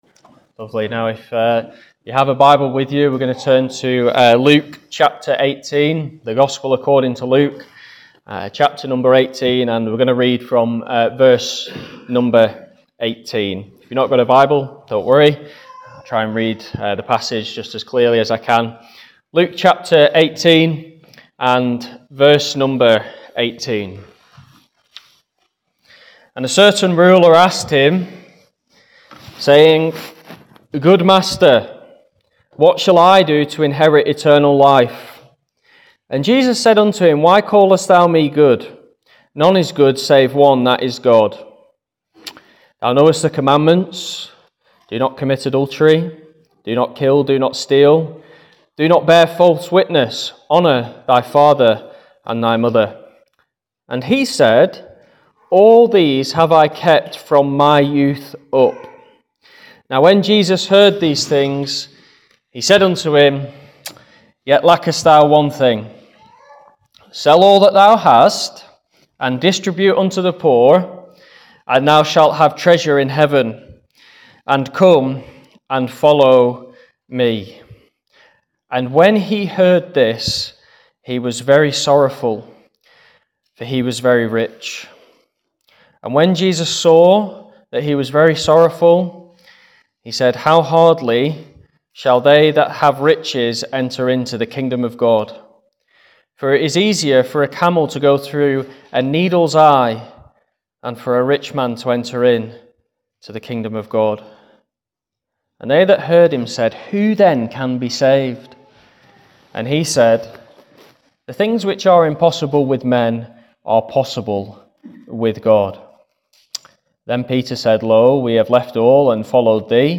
Various Gospel Messages